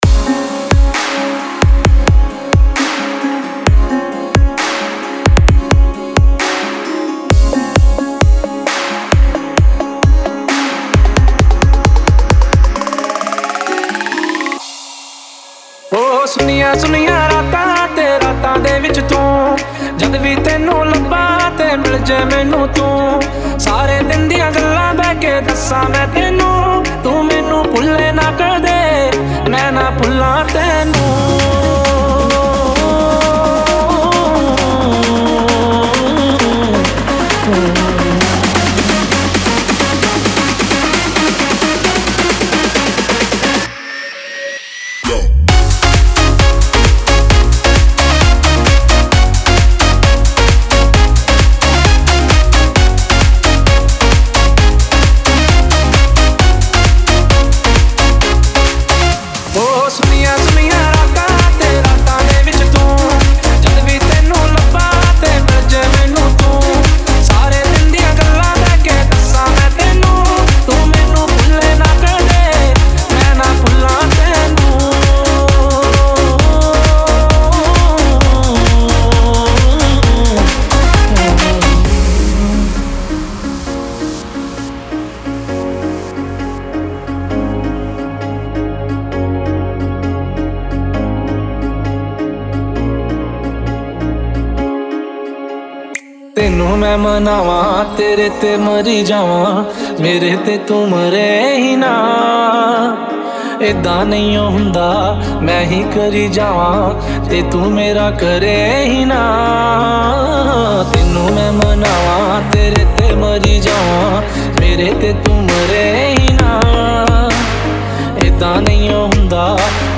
Category : Bollywood DJ Remix Songs